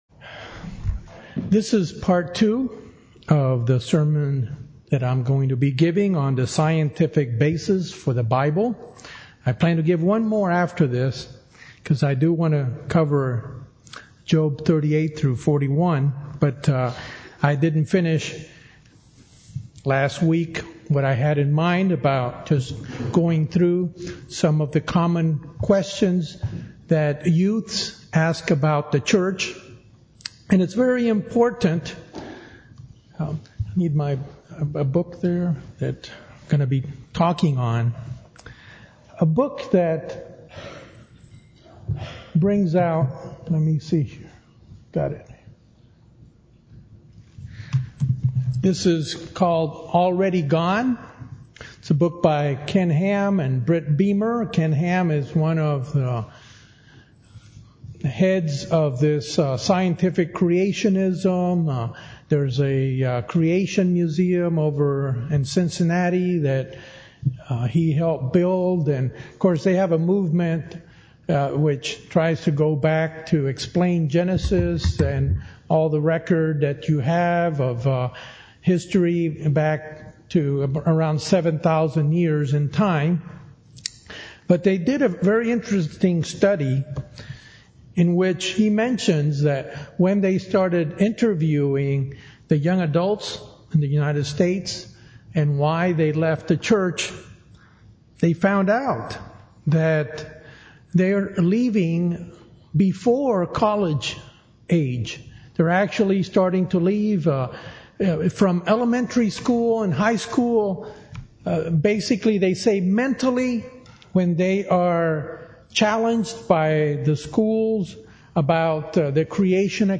This sermon covers the importance for youth to build their belief system on hard evidence for the Bible.